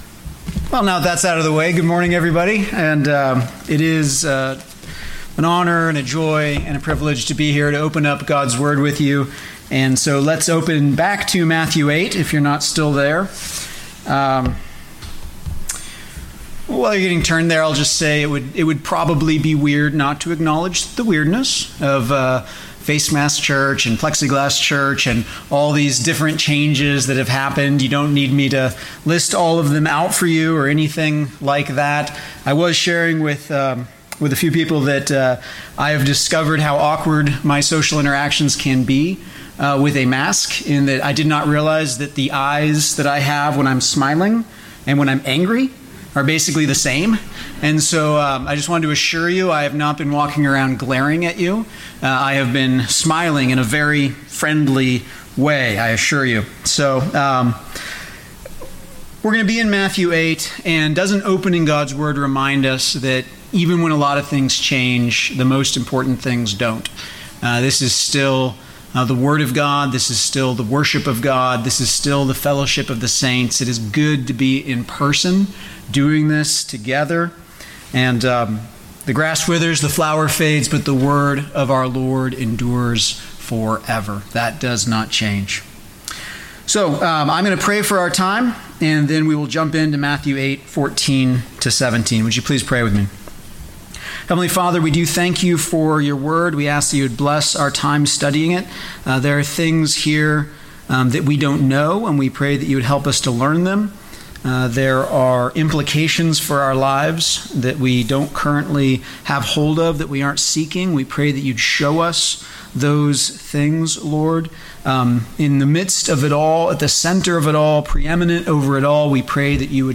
Worship Leader
Announcements Scripture
Sermon “A Cure for Death